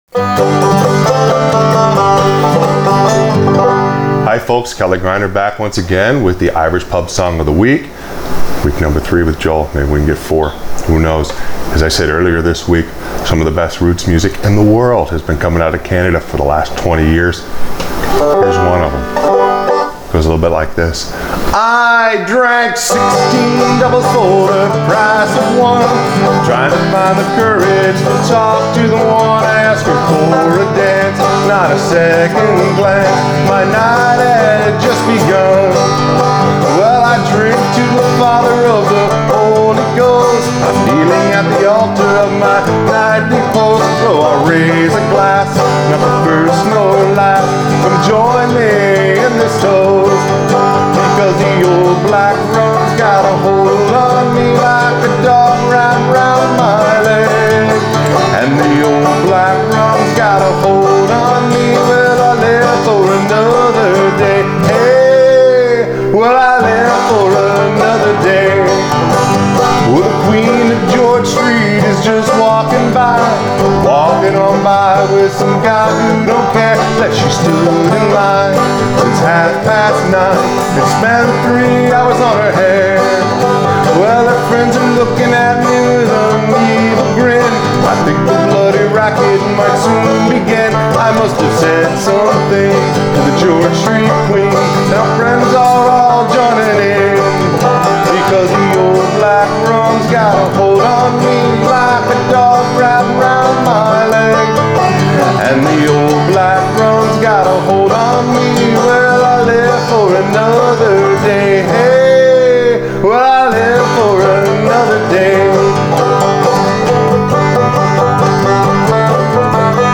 Accompaniment for Frailing Banjo